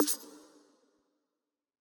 select-expand.mp3